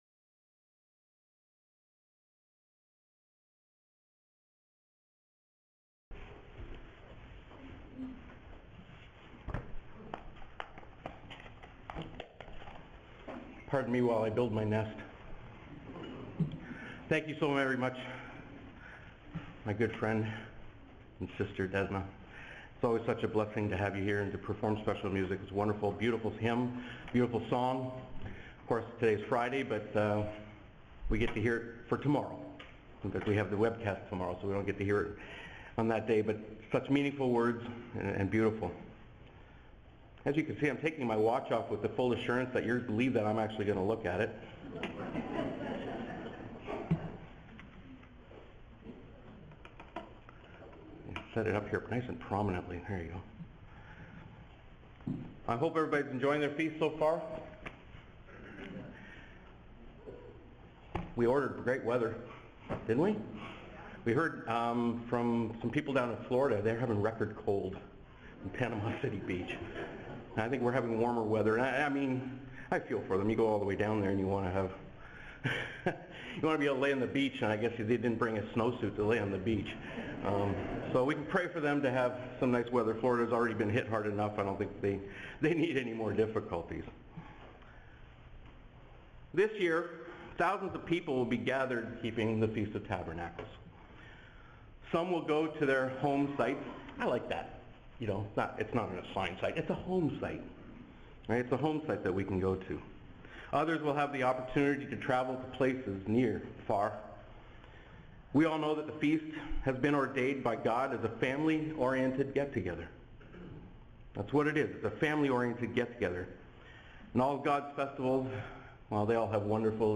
This sermon was given at the Midland, Ontario 2024 Feast site.